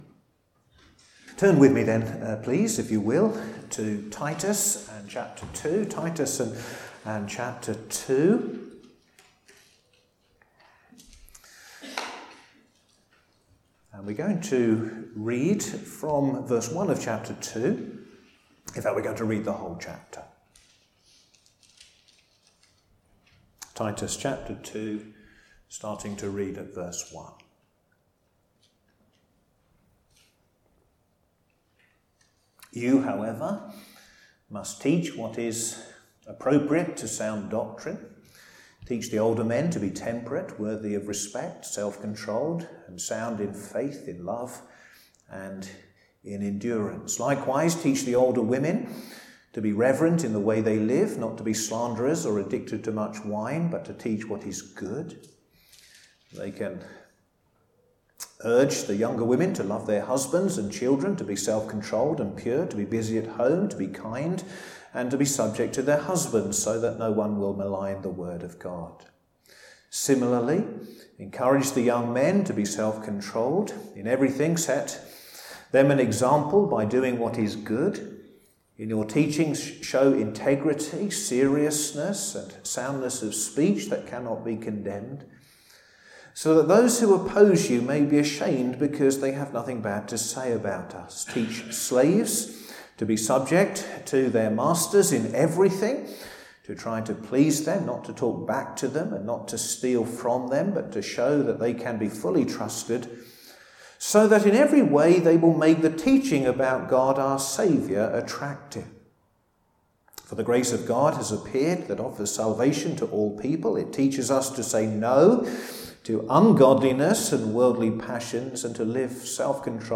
All sermons preached at Crockenhill Baptist Church